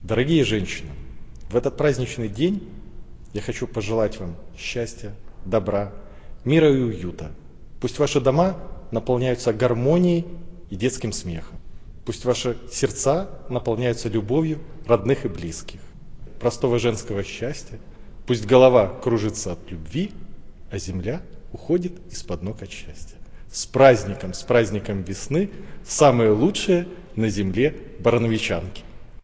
Председатель Барановичского горисполкома Максим Антонюк поздравил жительниц Барановичей с 8 Марта
Поздравления жительницам Барановичей адресовал председатель городского исполнительного комитета Максим Антонюк.